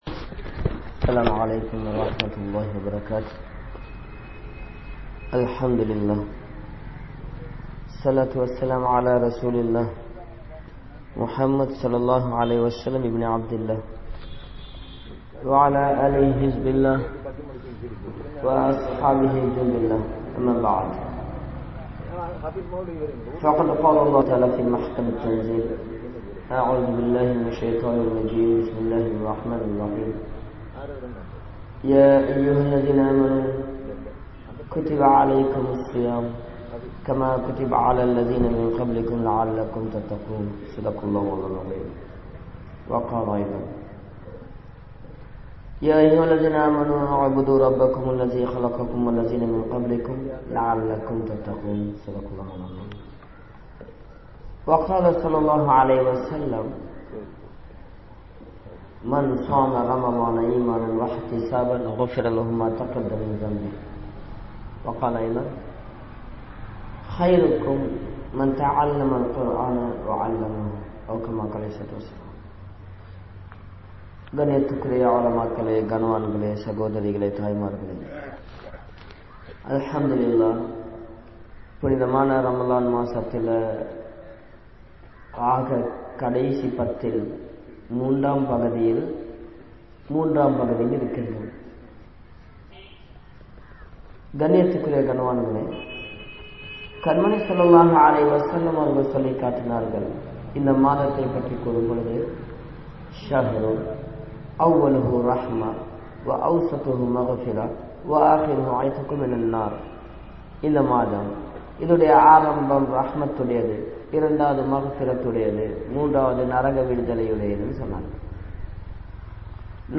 Indraya Aadai Murai | Audio Bayans | All Ceylon Muslim Youth Community | Addalaichenai